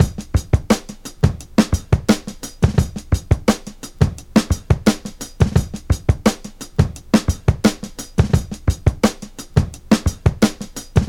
• 115 Bpm Drum Beat E Key.wav
Free breakbeat sample - kick tuned to the E note.
115-bpm-drum-beat-e-key-jhP.wav